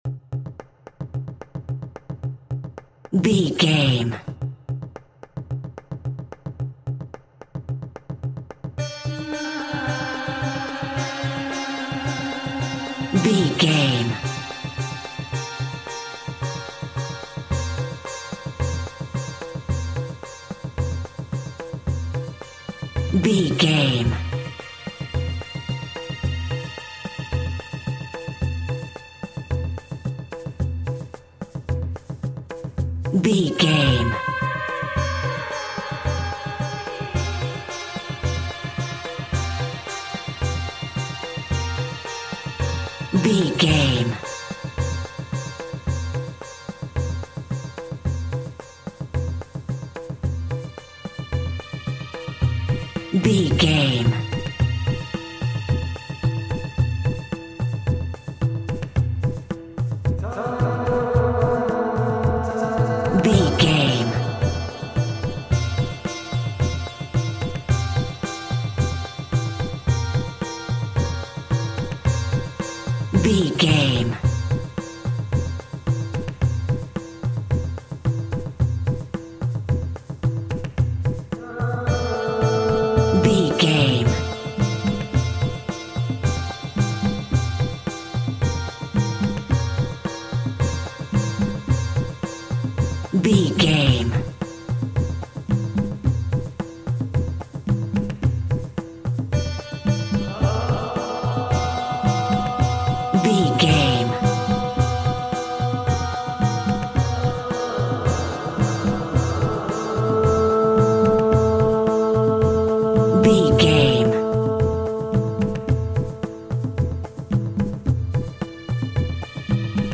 Diminished
D
World Music